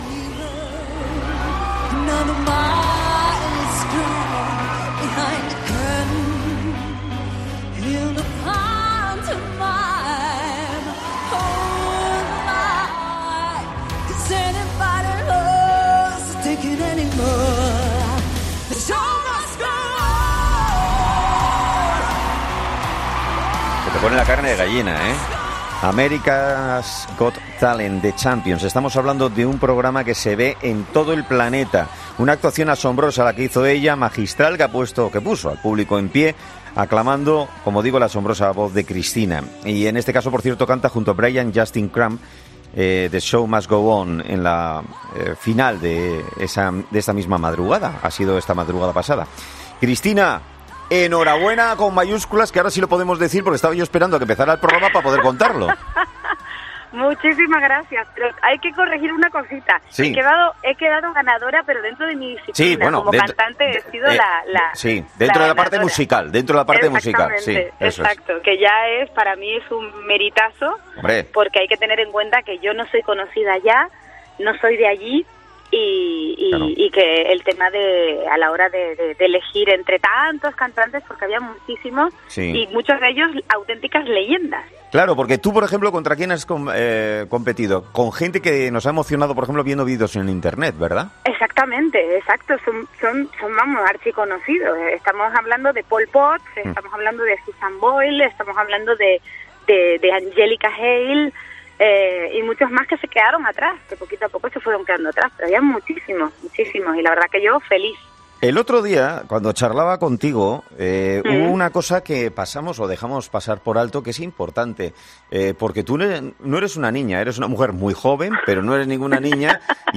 “Para mí es un 'meritazo' porque yo no soy conocida allí y había autenticas leyendas”, ha dicho este martes en 'Herrera en COPE'.
Sobre su paso por el concurso, ha dicho emocionada que “da mucho vértigo cuando ves desde dentro todo lo que sucede y de repente eres consciente de donde has llegado”.